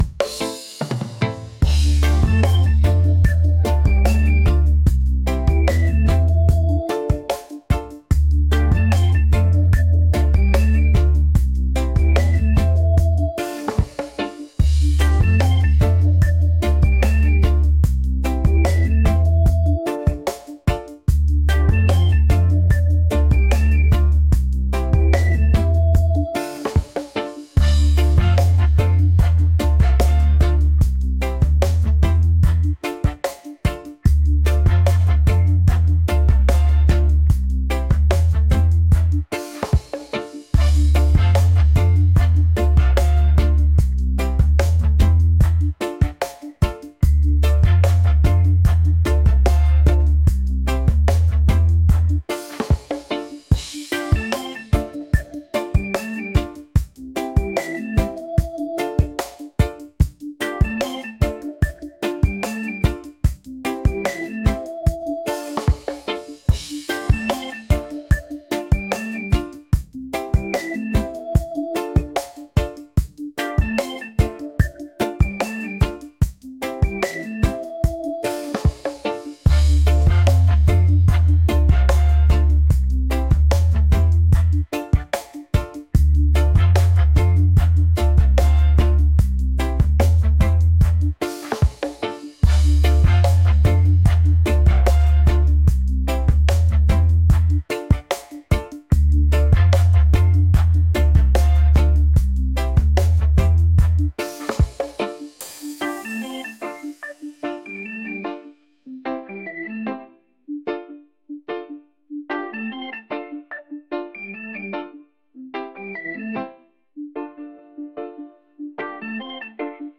reggae | groovy